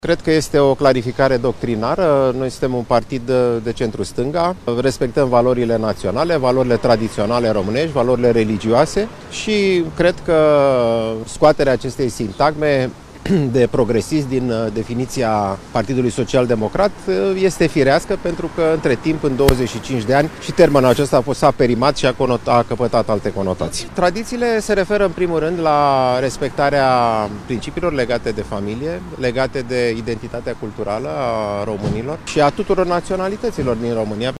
Purtătorul de cuvânt al PSD, Alexandru Rafila: „Cred că scoaterea acestei sintagme de «progresism» din definiția partidului PSD este firească”